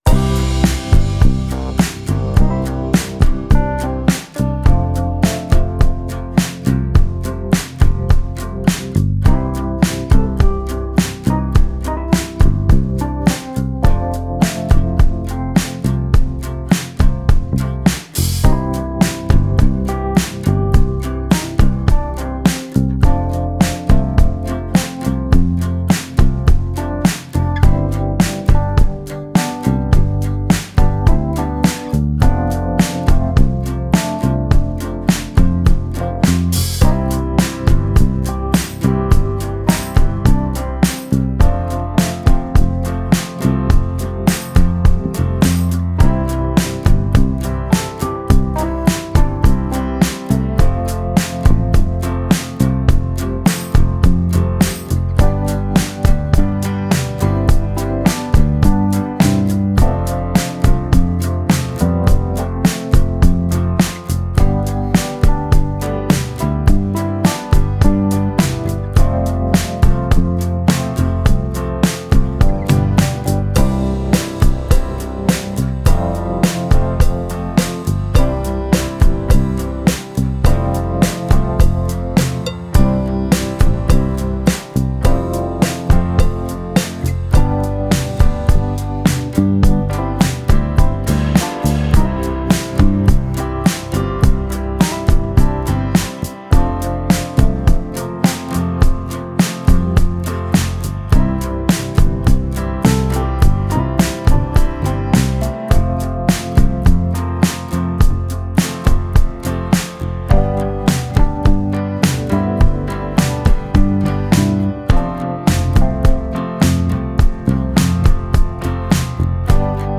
Genres: Pope